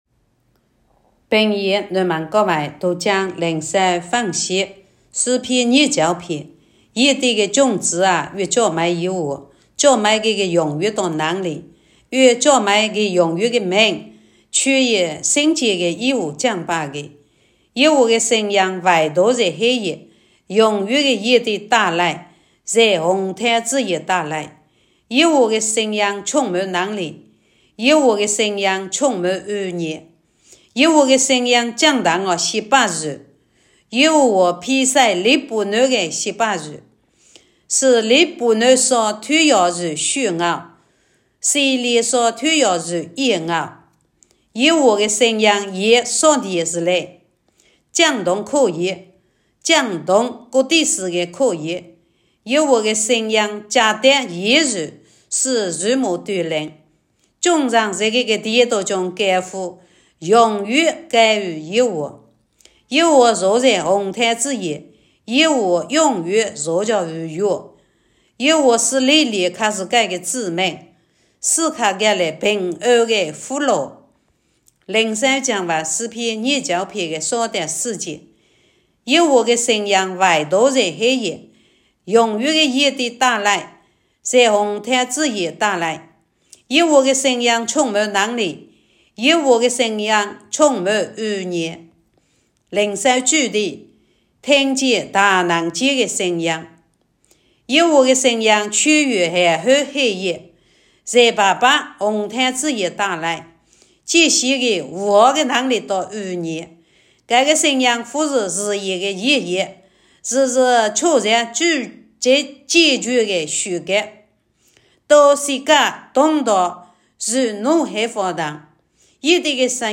平阳话朗读——诗29